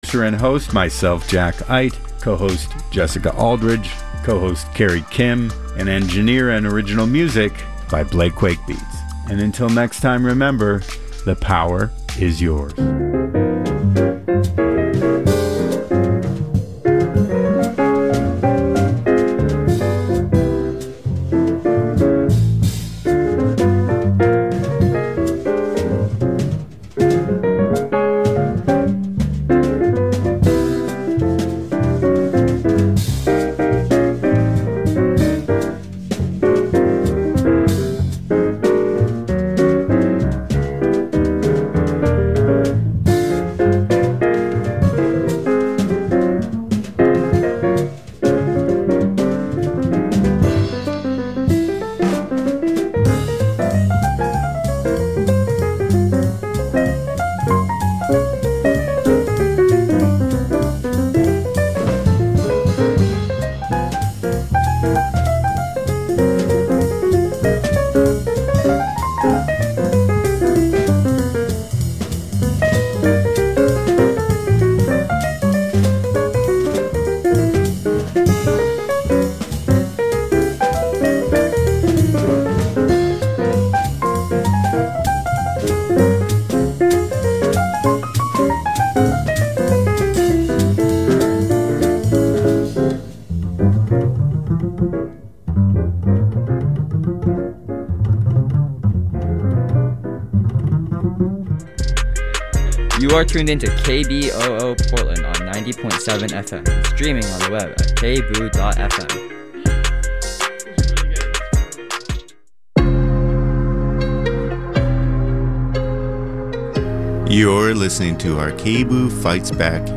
Twice a month I'll talk with an author about their book.